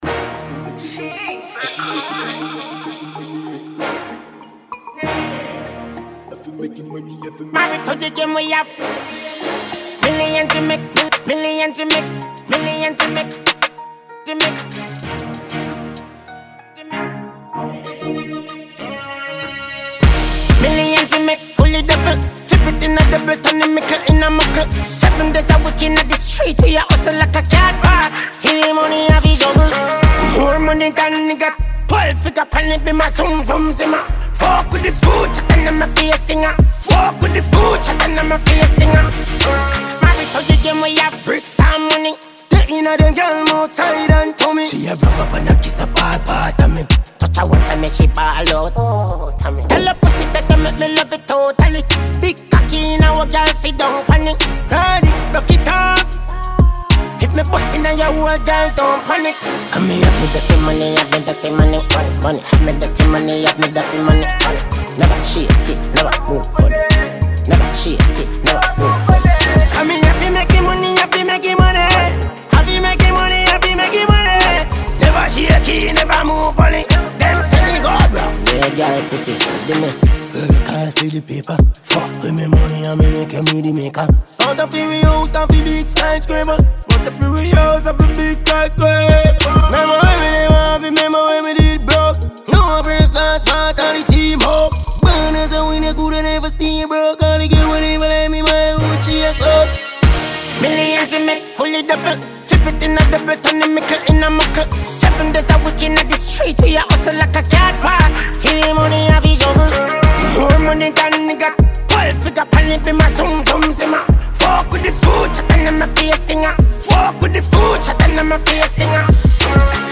Jamaican reggae singer